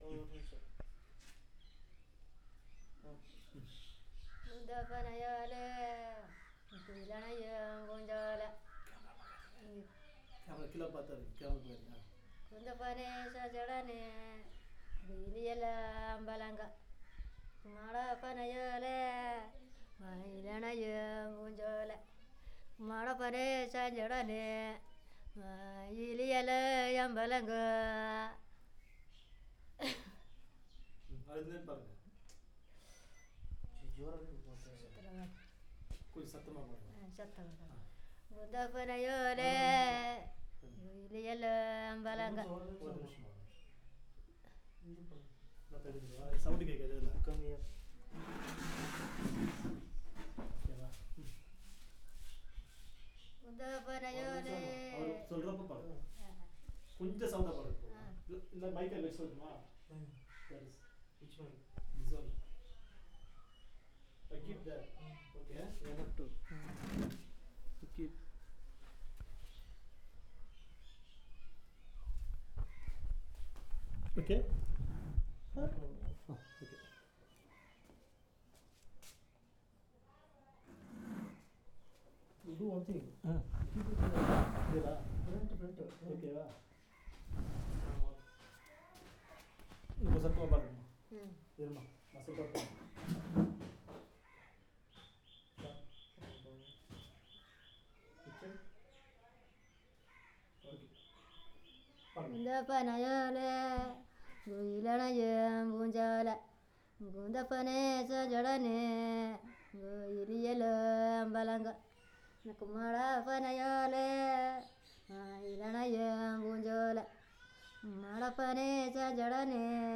Performance of traditional song